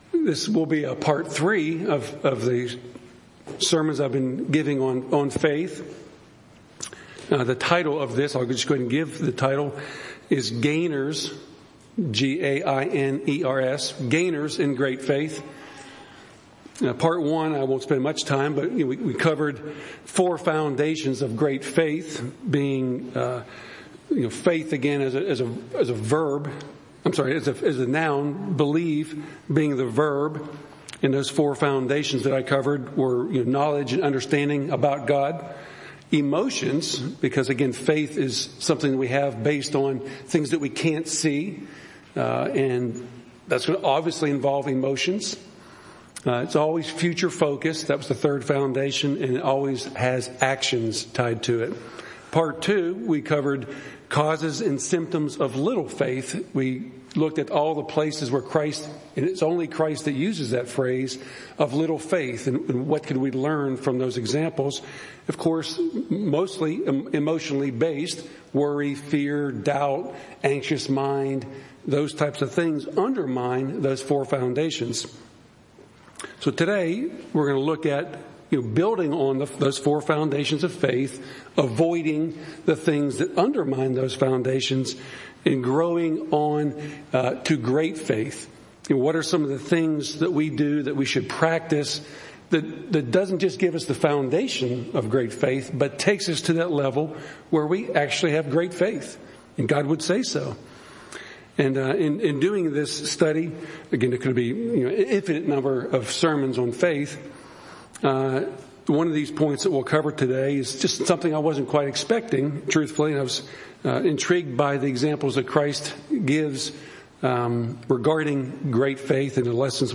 How to avoid things that undermine the four foundations of faith, and instead growing in them, going on to great faith. Part 3 of a sermon series on Faith.